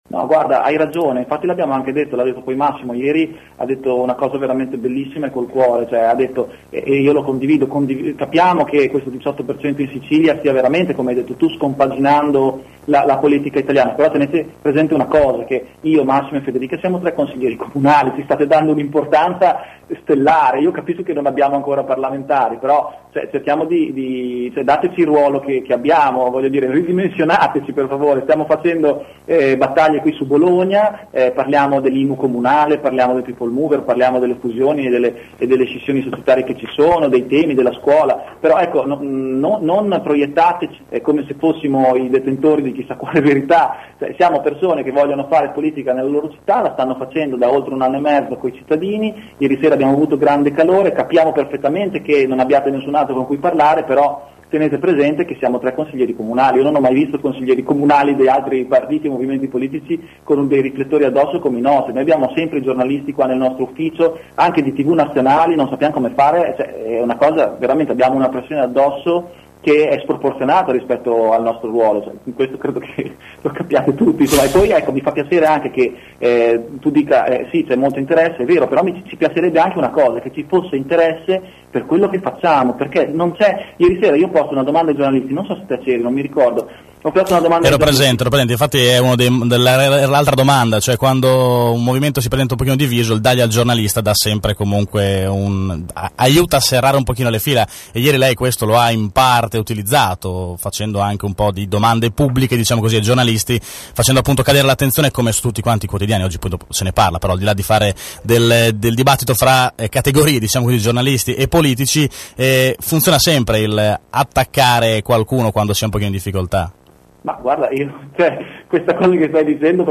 Su questo abbiamo chiesto un commento a Piazza che, nel corso dell’assemblea, aveva accusato i giornalisti presenti in sala di fare “gossip politico”, occupandosi del movimento solo per via delle polemiche interne.